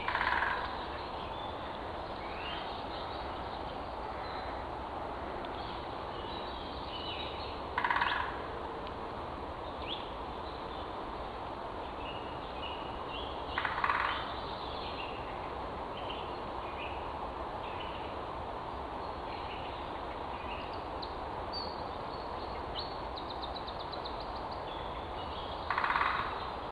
The typical drumming of a Lesser spotted Woodpecker is longer (a second plus) with slower individual taps than the machine gun burst of the Greater spotted. The effect is to give an impression of a second of two or rapid ‘tapping’ rather than the Great spotted’s rapid strikes which merge into one another.
Great spotted Woodpecker.
GSWdrum.ogg